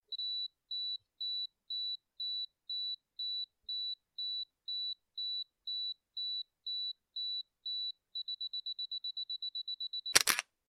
taimer-kamery_24823.mp3